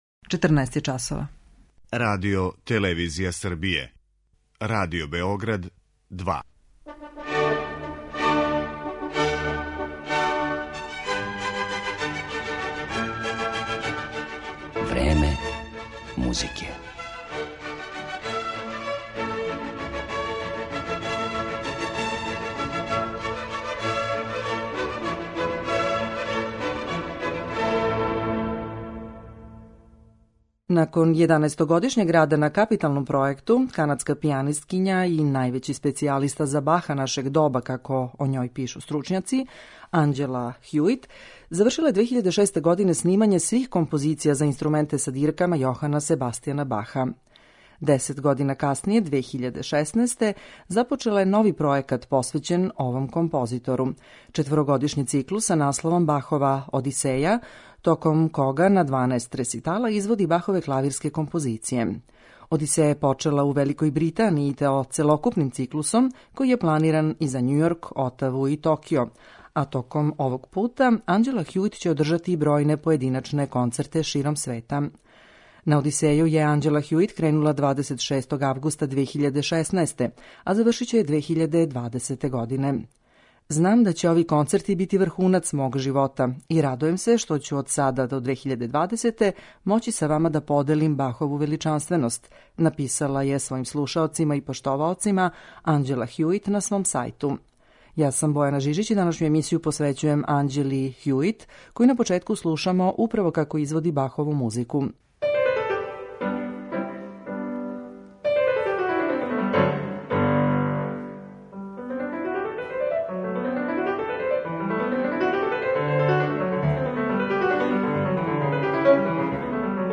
Једном од највећих савремених специјалиста за извођење музике Јохана Себастијана Баха на клавиру, канадској пијанисткињи Анђели Хјуит, посвећена је данашња емисија.
Поред Бахових дела, ова врхунска уметница специфичног сензибилитета и укуса, изводиће и композиције Жана Филипа Рамоа, Лудвига ван Бетовена и Мориса Равела.